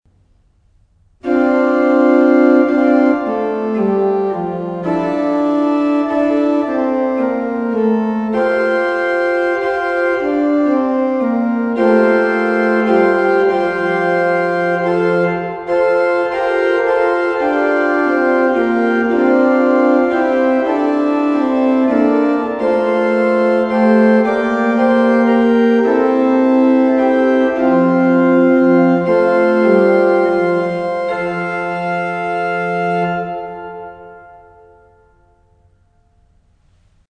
Orgelvorspiele
Hier finden Sie einige Orgelvorspiele zu Liedern aus dem Gotteslob.
gg_848_herr_ich_glaube_orgelvorspiel.mp3